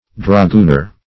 Dragooner \Dra*goon"er\